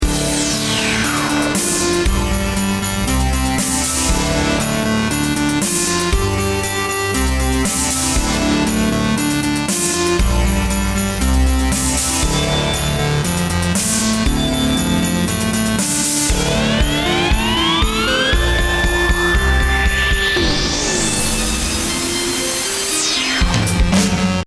Jam I'd intended to play after winning a battle, with a sci-fi theme.
space fanfare.mp3